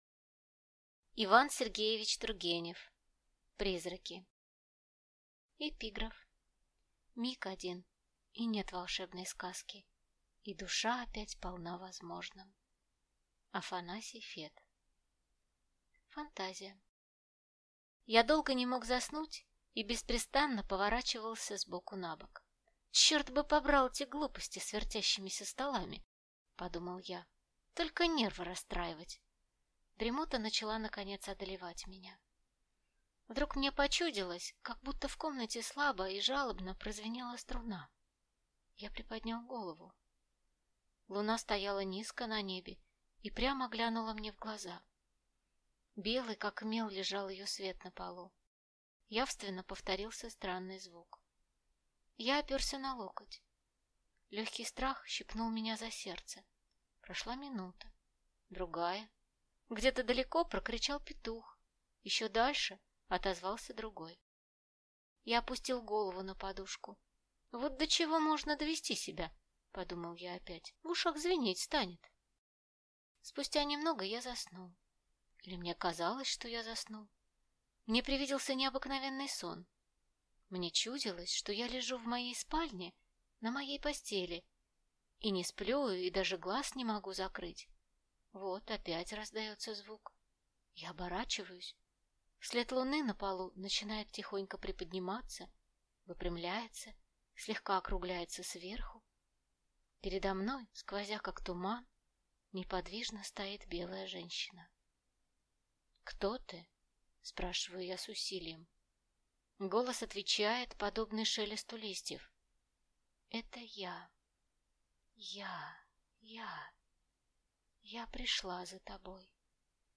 Aудиокнига Призраки